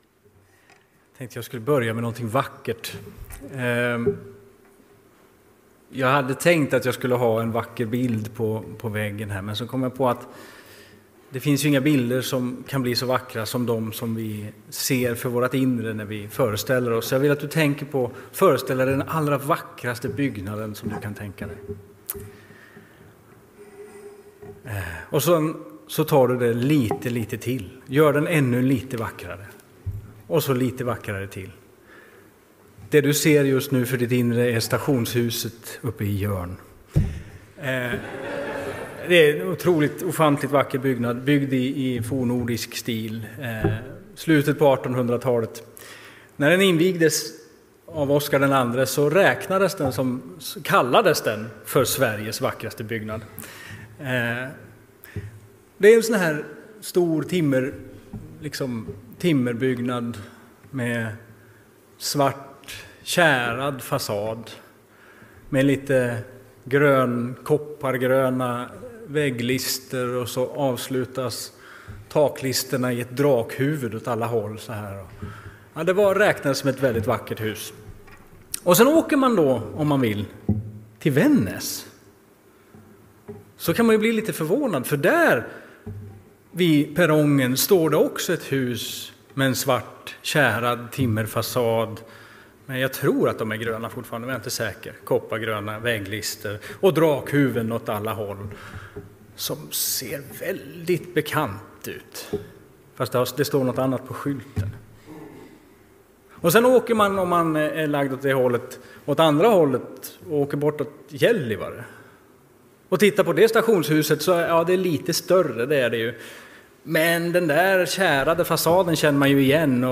predikar.